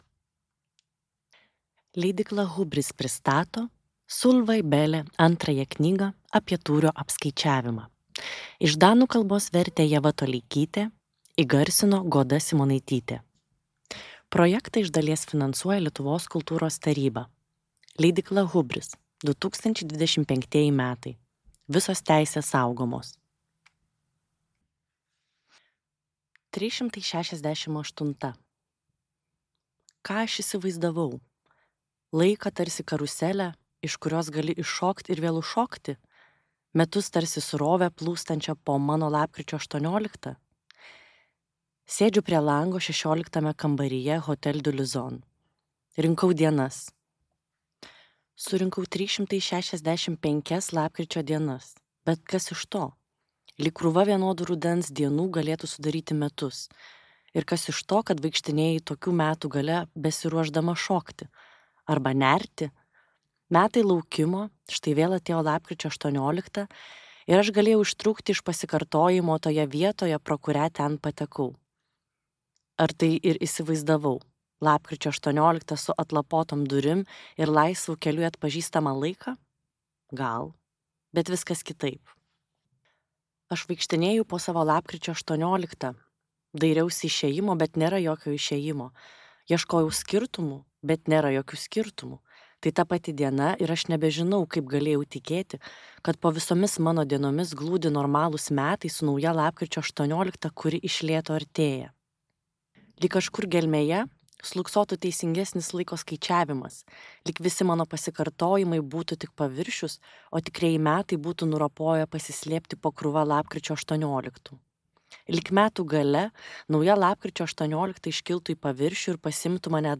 Danų rašytojos Solvej Balle audioknyga „Apie tūrio apskaičiavimą“ – antroji dalis (iš septynių).